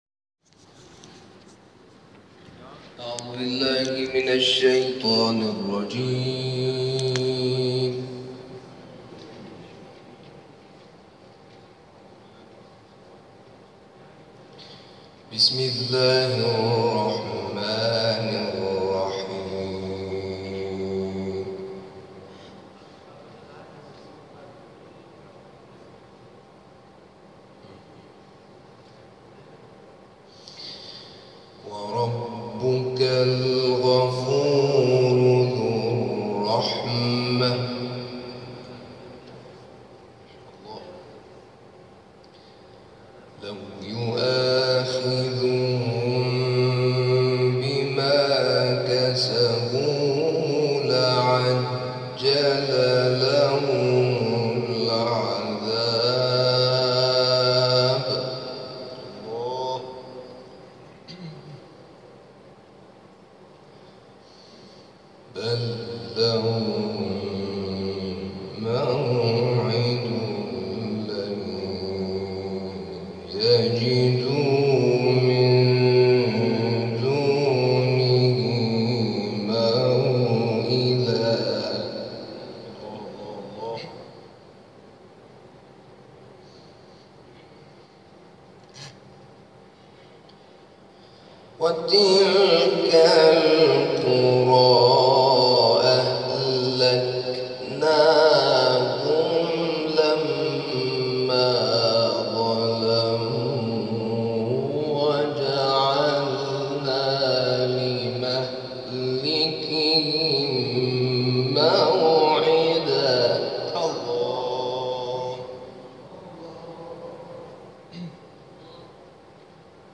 شب گذشته، 31 خردادماه، در مسجد جامع المهدی(عج) برگزار شد.